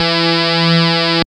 74.10 BASS.wav